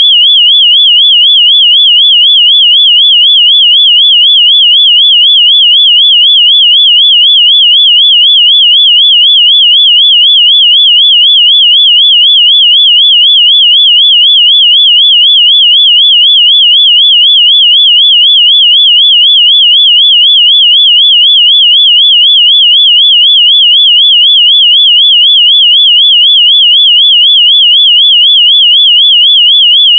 下面是用Multi-Instrument的信号发生器生成的30秒长的标准测试信号（WAV文件），可供下载。